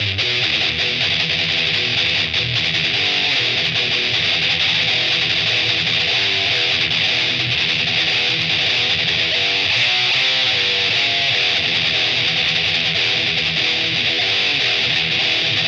• Play the guitar sloppily
• Riffs like those played by Megadeth (very unreliable)